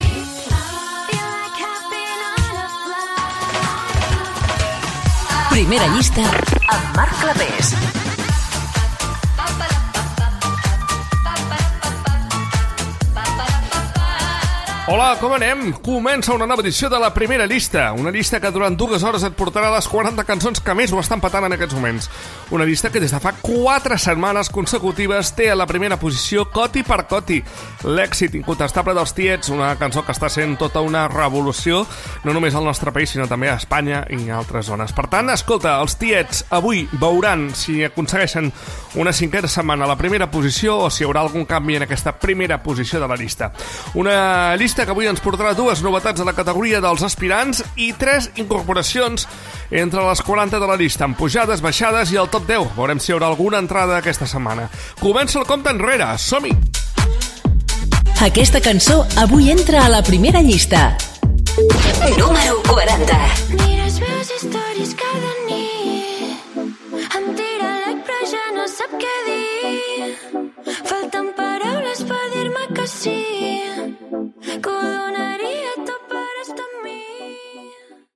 Careta del programa, presentació del programa, tema que està al número 1, tema que entre a la posició 40.
Musical